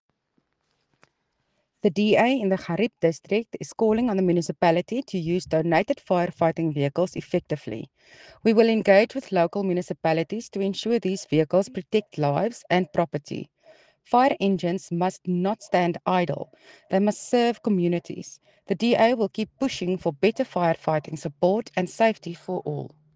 Afrikaans soundbites by Cllr Mariska Potgieter and